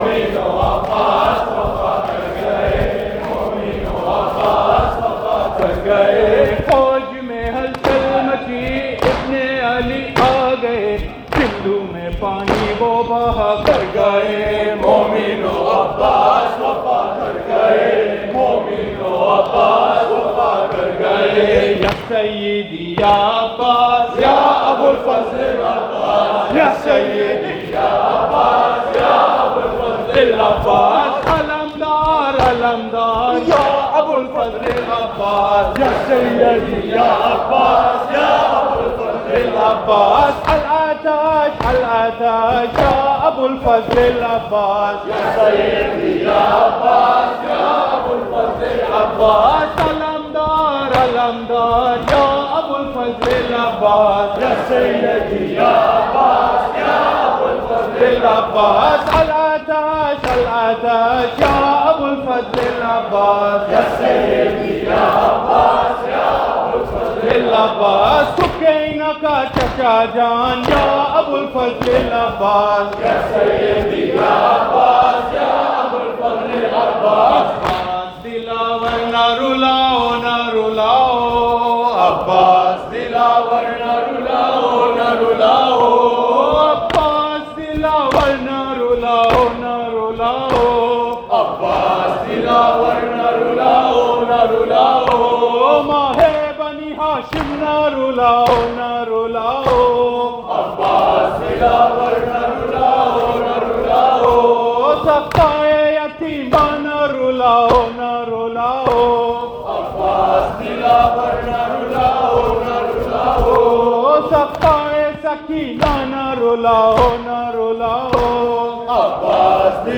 Ending Matams